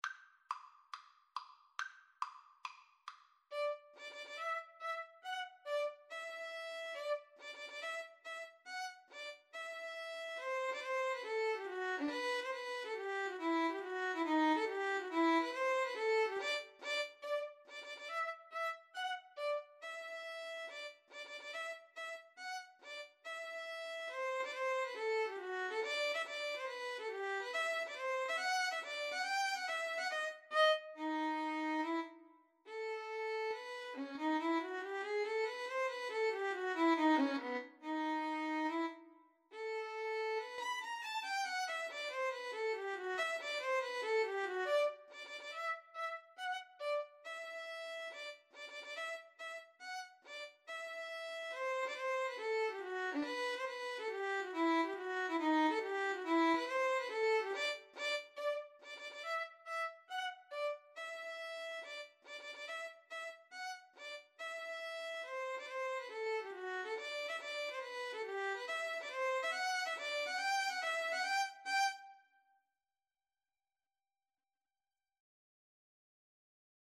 4/4 (View more 4/4 Music)
Tempo di marcia =140
Classical (View more Classical Violin Duet Music)